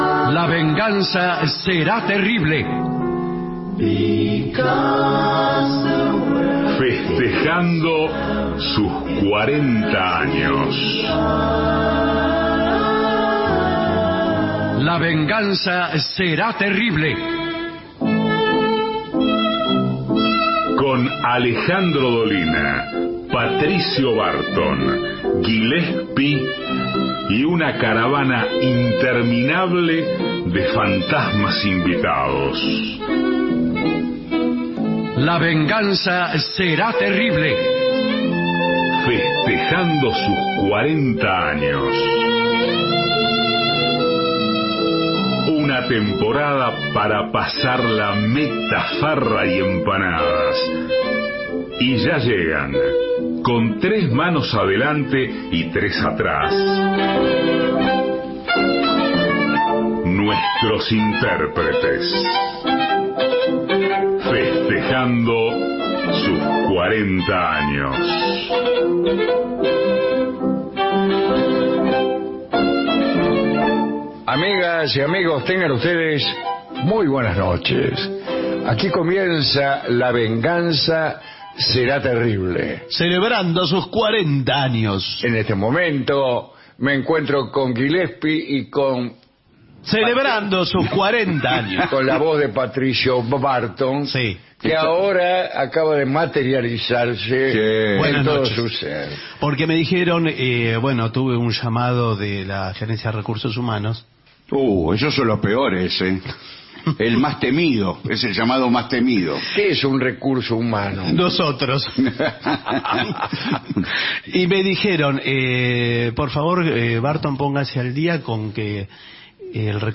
todo el año festejando los 40 años Estudios AM 750 Alejandro Dolina